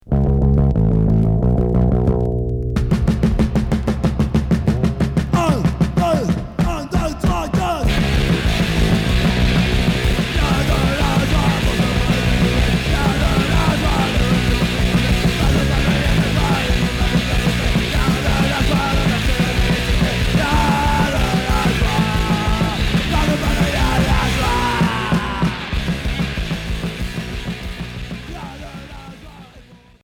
Street punk